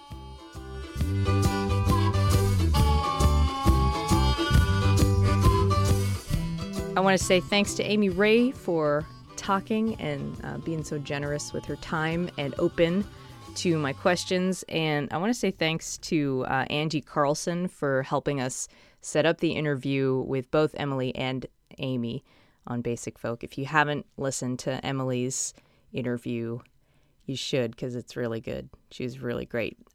(recorded from webcast)
08. announcer (0:29)